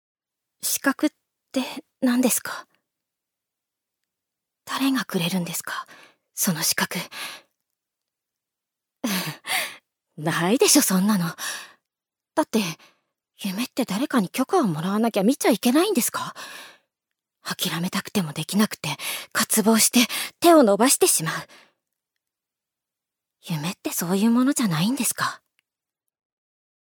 女性タレント
セリフ３